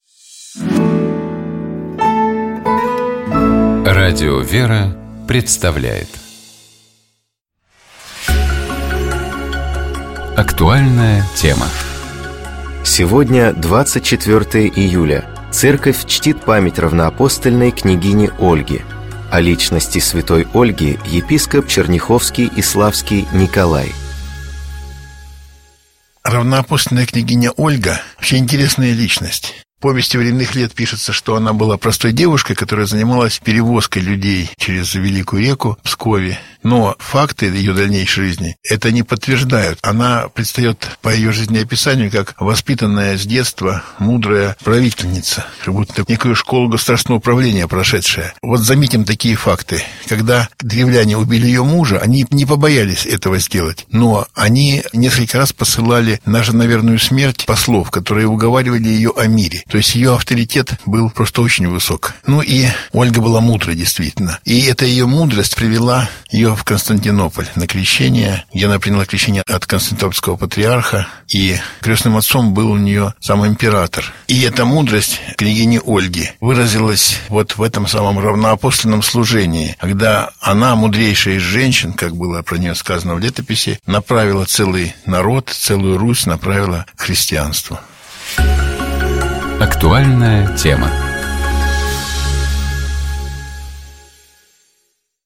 4 ноября, в Праздник Казанской иконы Божьей Матери и в День народного единства, Святейший Патриарх Московский и всея Руси Кирилл совершил Божественную Литургию в Успенском соборе Московского Кремля.
На проповеди после богослужения Святейший Патриарх говорил о важности сохранения православной веры в России.